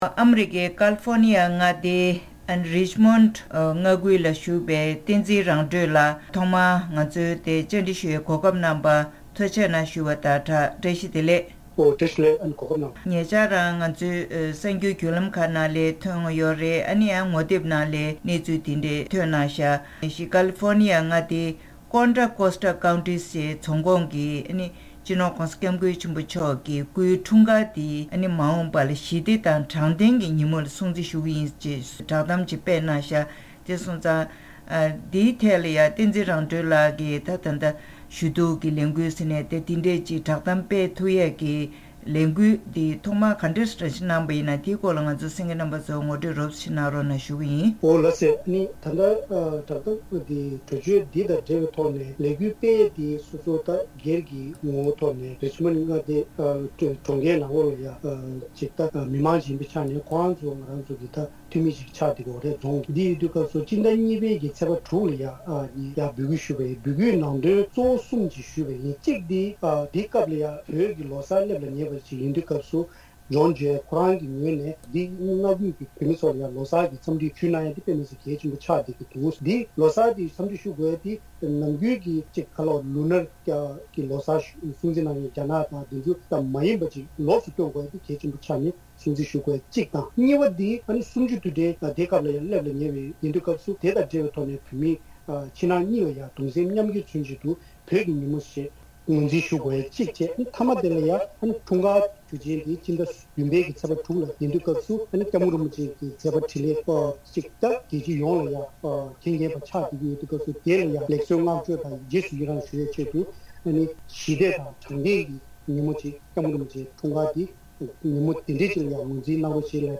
བཀའ་འདྲི་ཞུས་པ་ཞིག་གསན་གནང་གི་རེད།